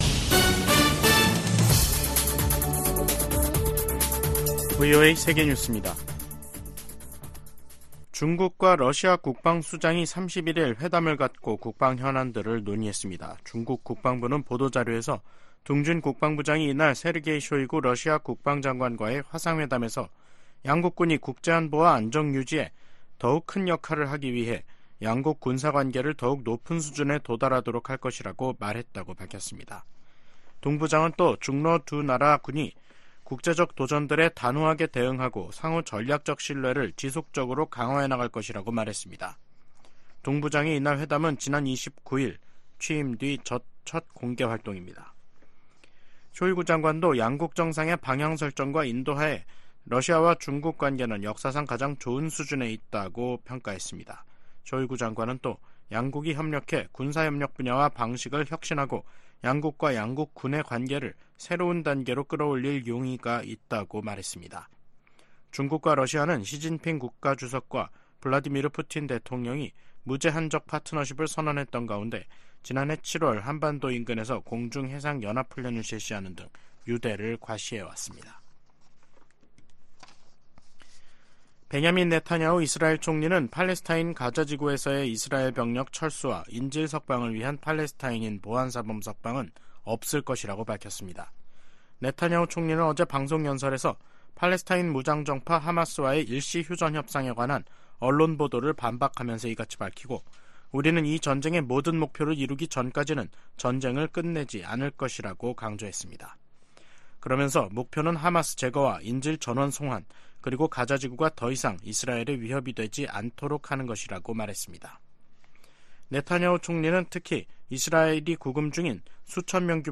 세계 뉴스와 함께 미국의 모든 것을 소개하는 '생방송 여기는 워싱턴입니다', 2024년 1월 31일 저녁 방송입니다. '지구촌 오늘'에서는 미-중 대표단이 베이징에서 만나 마약성 진통제 펜타닐 대응 실무그룹을 출범시킨 소식 전해드리고, '아메리카 나우'에서는 알레한드로 마요르카스 국토안보부 장관 탄핵소추안이 하원 국토안보위원회를 통과한 이야기 살펴보겠습니다.